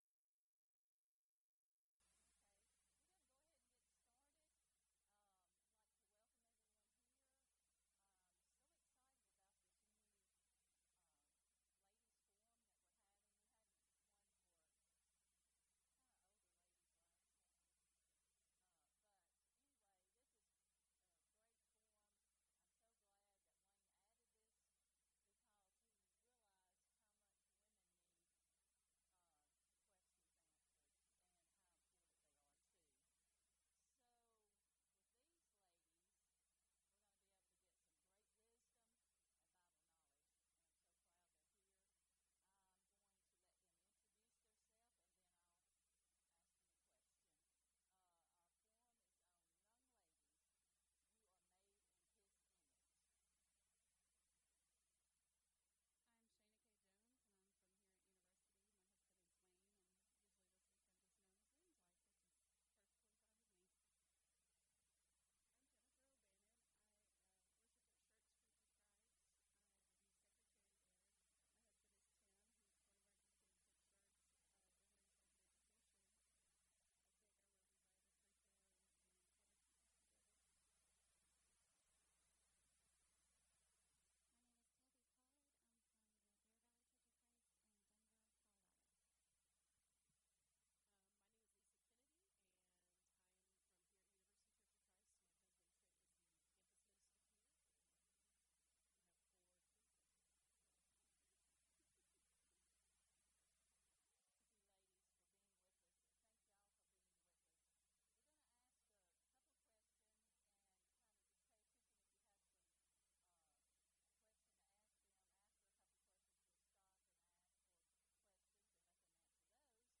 Title: LADIES: Forum - Young Ladies, You Are Made In His Image Speaker(s): Various
Event: 2017 Focal Point
Ladies Sessions